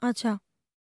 TALK 7.wav